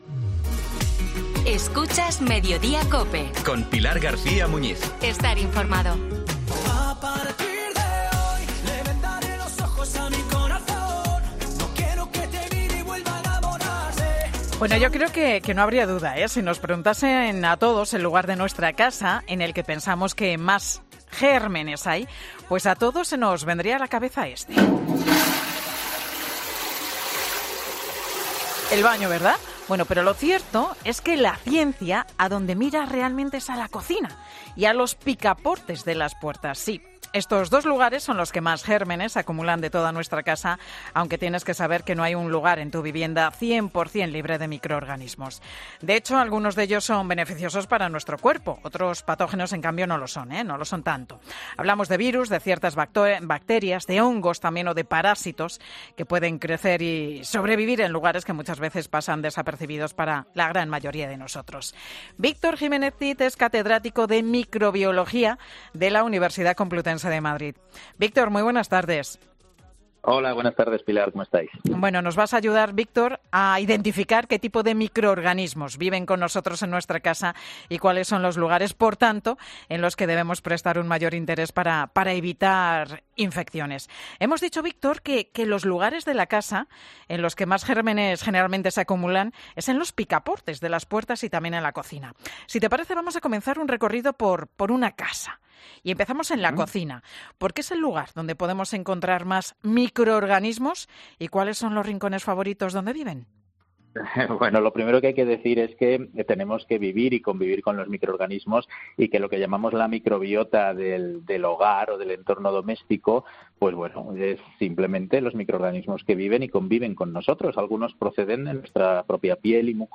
Algunos de estos patógenos nos pueden ocasionar infecciones, pero "no hay que ser obsesivos", aconseja en 'Mediodía COPE' un catedrático de microbiología